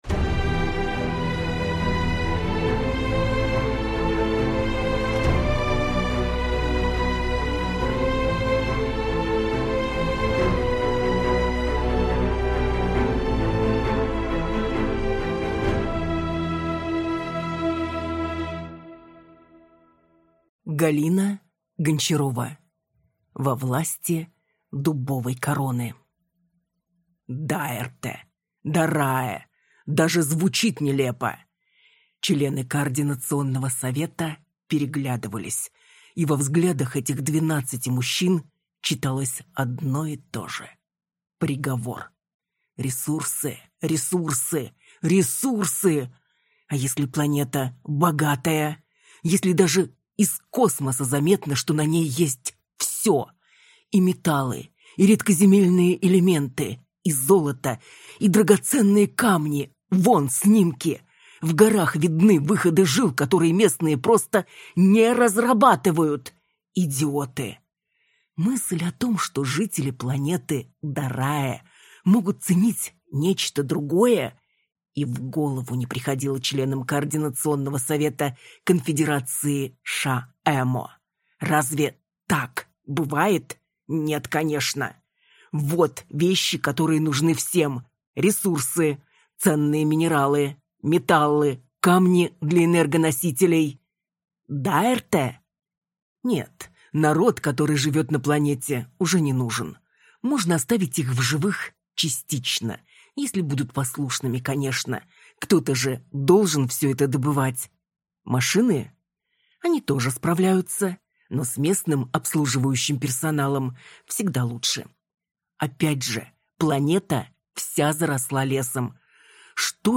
Аудиокнига Во власти Дубовой короны | Библиотека аудиокниг